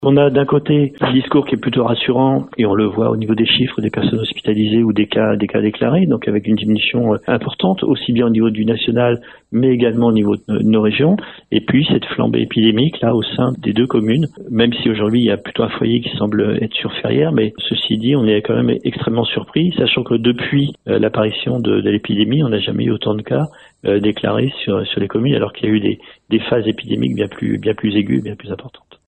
Alain Tréton, maire de Benon et président du Sivos qui regroupe les écoles de Benon et Ferrières, suit l’évolution de la situation de près, en lien avec l’Académie et l’Agence régionale de santé.